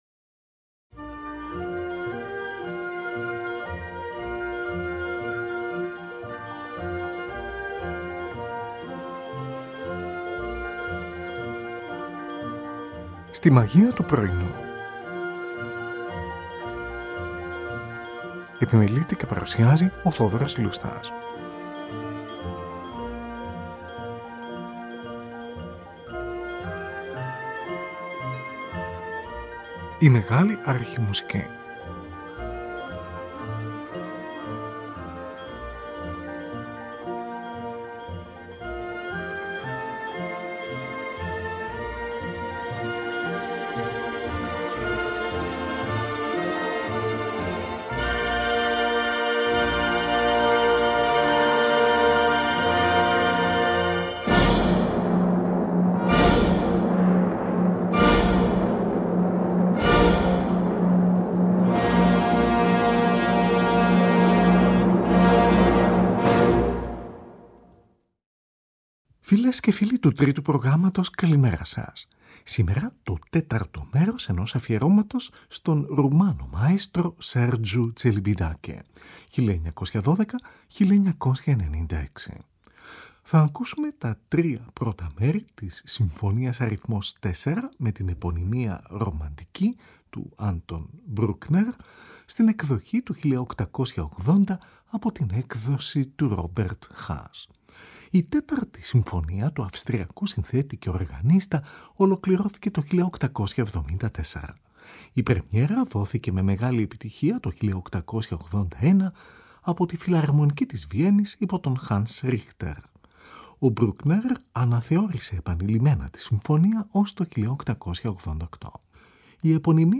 Τη Φιλαρμονική του Μονάχου διευθύνει ο Sergiu Celibidache , από ζωντανή ηχογράφηση, στις 16 Οκτωβρίου 1988 .